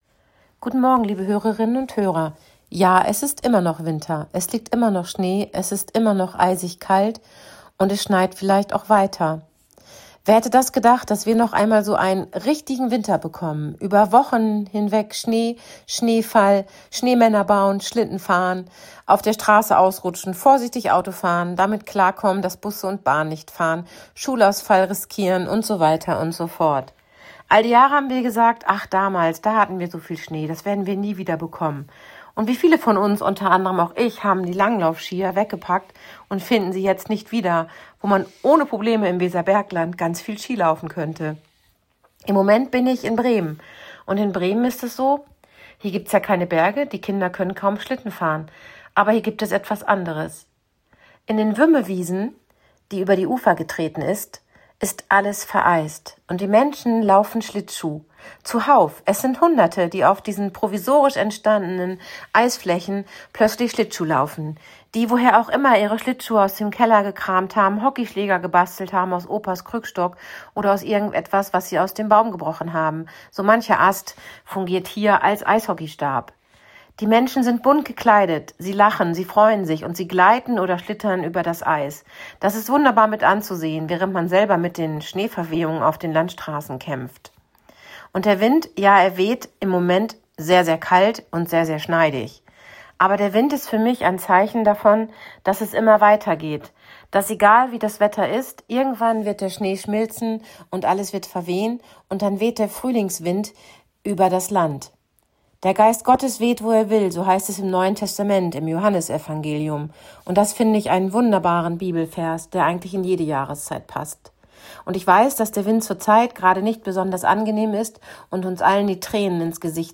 Radioandacht vom 4. Februar